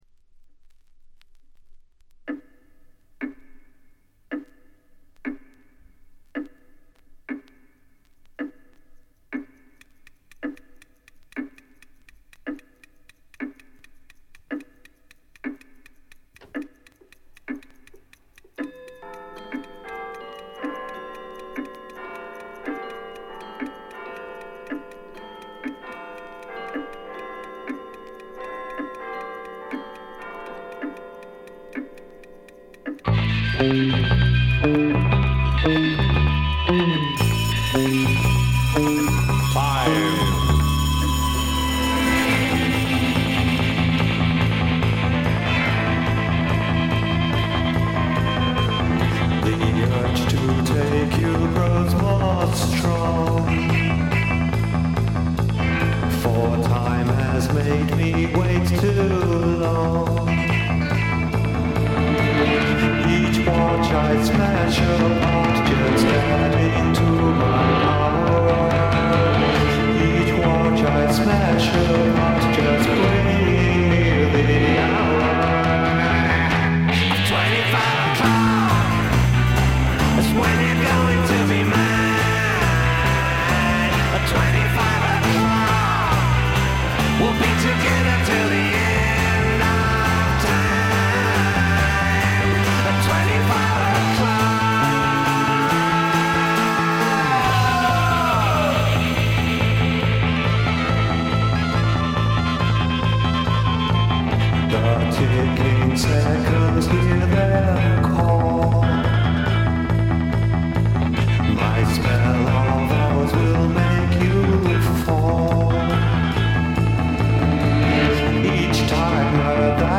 ひとことで言って完璧なサイケデリック・サウンドです。80年代に再構築された完璧なポップ・サイケ・ワールド。
試聴曲は現品からの取り込み音源です。
Mellotron, Piano, Organ, Guitar [Fuzz-tone Guitar]
Electric Bass
Drums [Drum Kit]